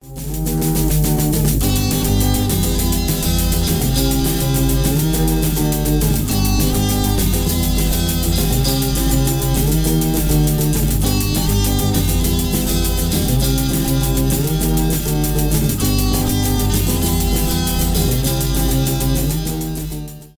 панк-рок , без слов